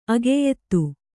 ♪ ageyettu